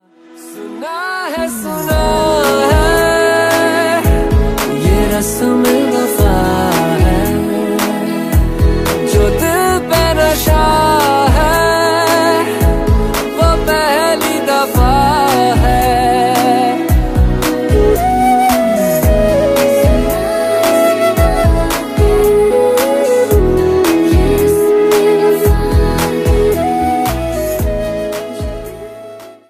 Download free Bollywood mp3 ringtones.
romantic ringtones